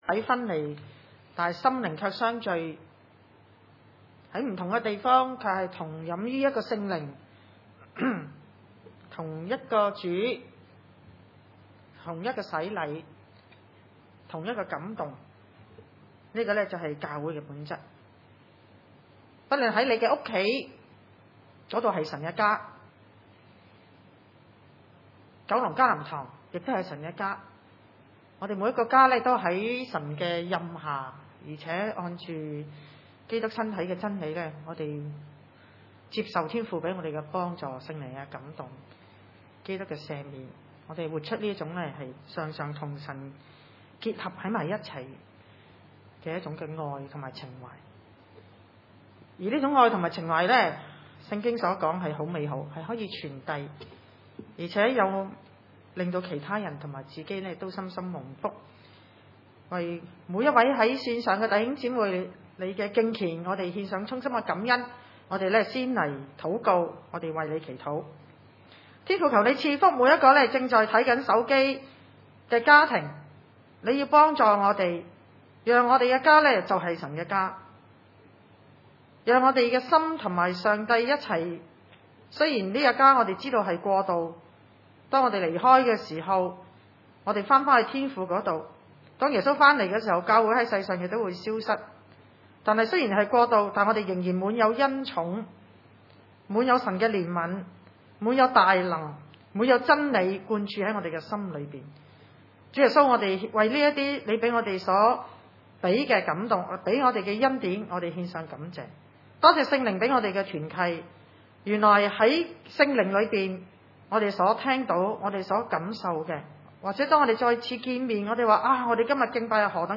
帖前二6-12 崇拜類別: 主日午堂崇拜 經文：帖撒羅尼迦前書二章6-12節（聖經 ‧ 新約） 6.